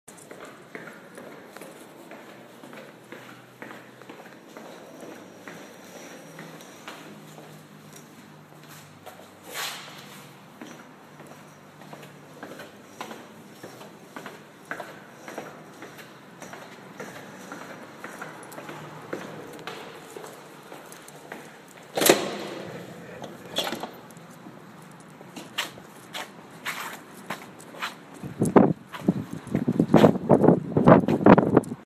Location: Walking down the stairs and opening the door of the Netherspan at approximately 11:30pm.
Sounds heard: door opening and closing, footsteps, movement of gravel on ground.